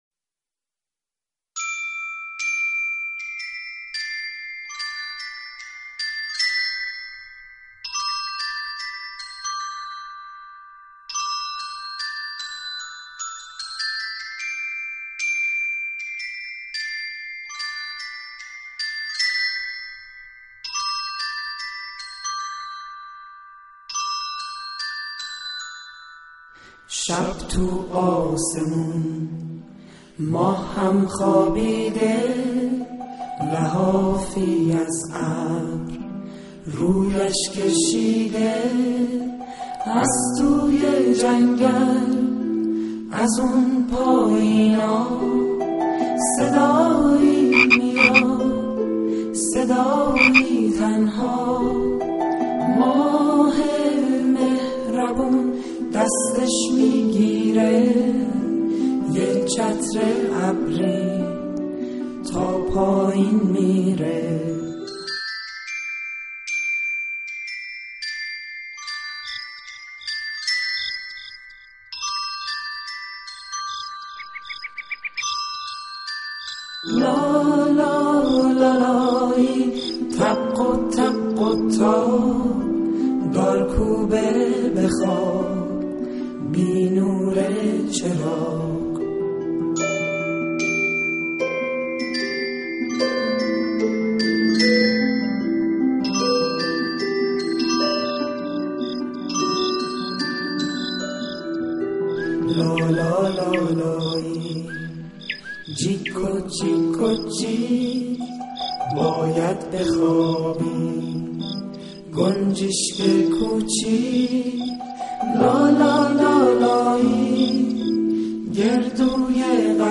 • لالایی آهنگ لالایی